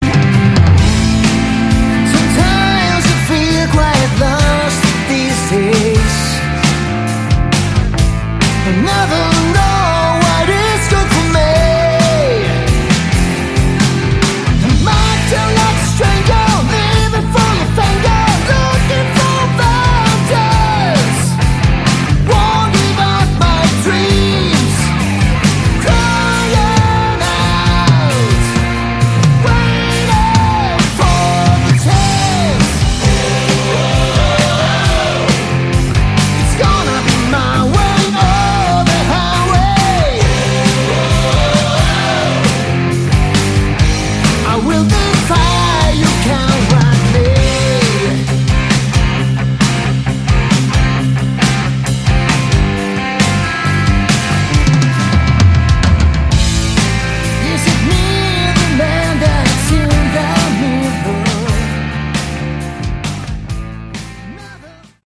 Category: Hard Rock
guitar
bass
lead vocals
drums